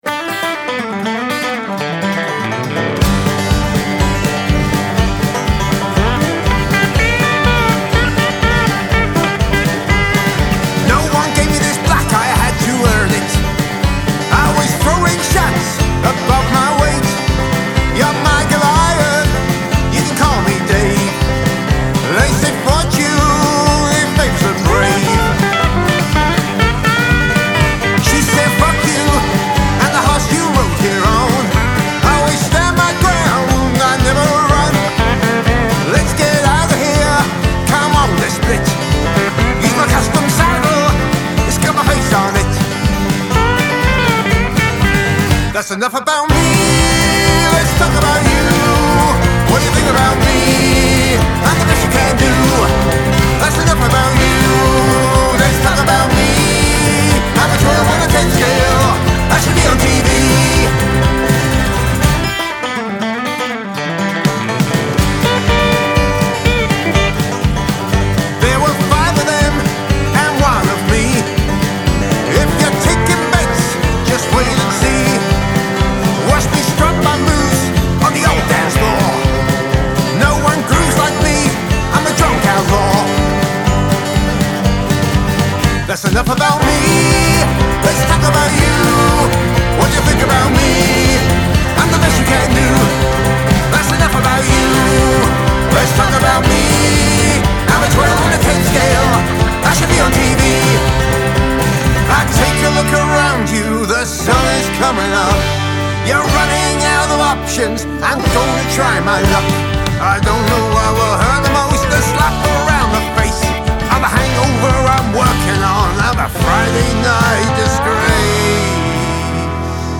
Male Vocal, Guitar, Banjo, Bass Guitar, Drums